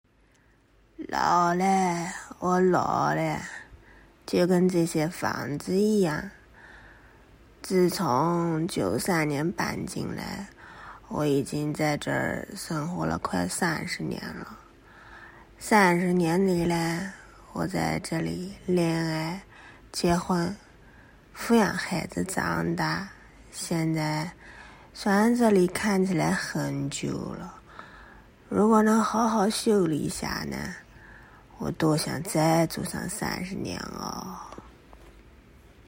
南京话_